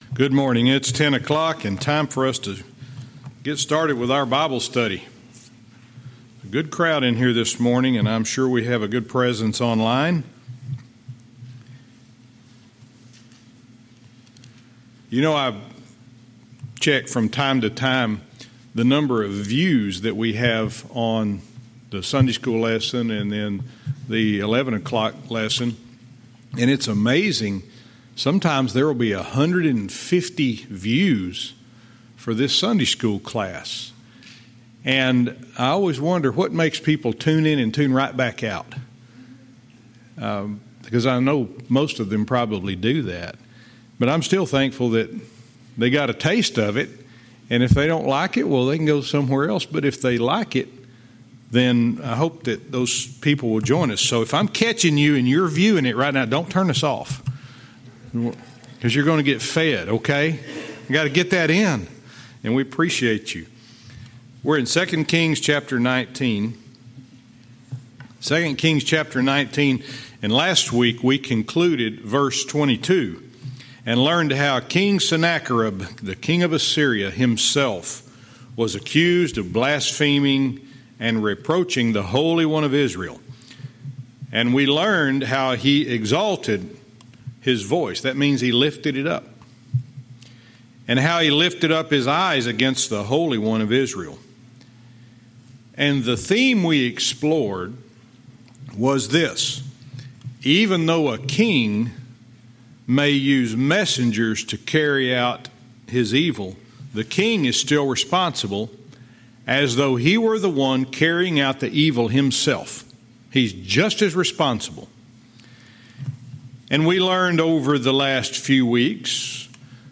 Verse by verse teaching - 2 Kings 19:23